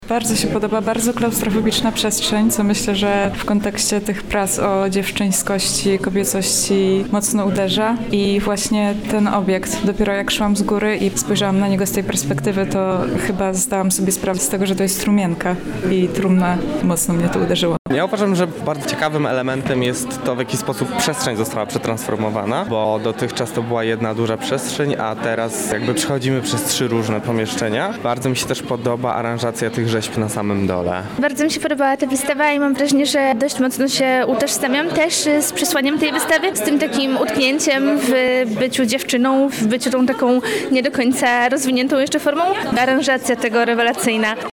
Na miejscu były nasze reporterki: